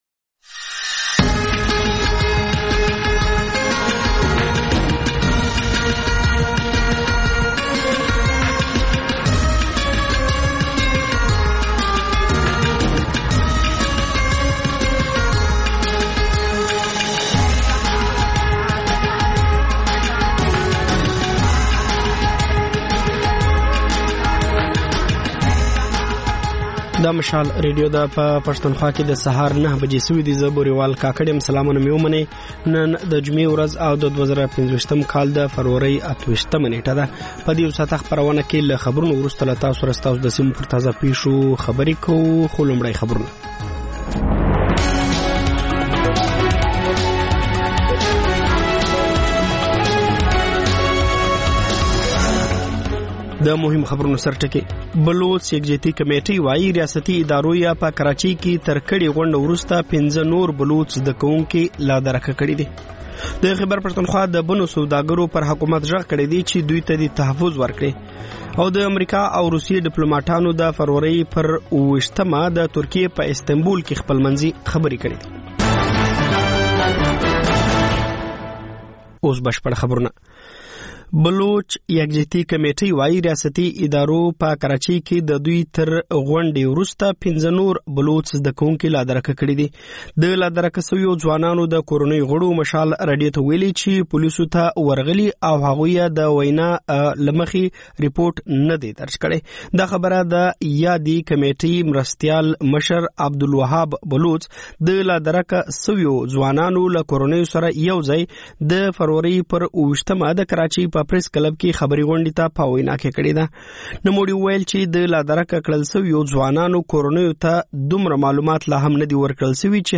دا د مشال راډیو لومړۍ خپرونه ده چې پکې تر خبرونو وروسته رپورټونه، له خبریالانو خبرونه او رپورټونه او سندرې در خپروو.